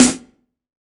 SNARE 050.wav